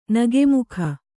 ♪ nage mukha